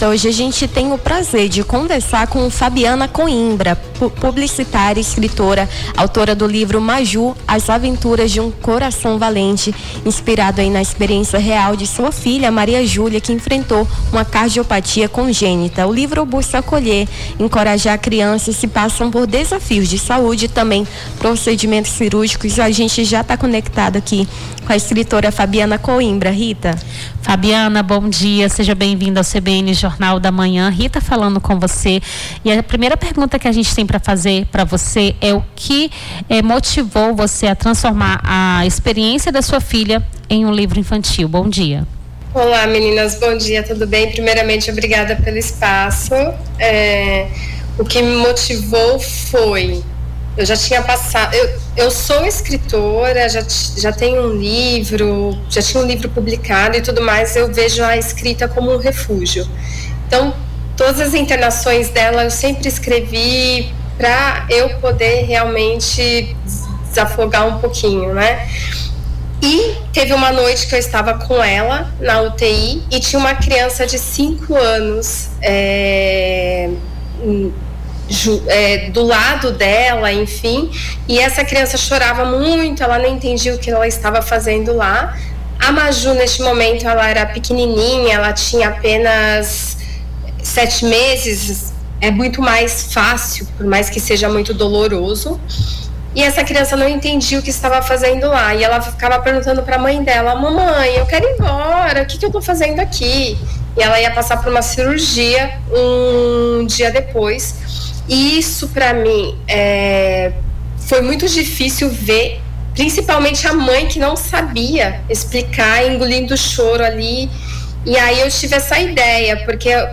Nome do Artista - CENSURA - ENTREVISTA UMA AVENTURA PRA PEQUENOS PACIENTES (15-05-25).mp3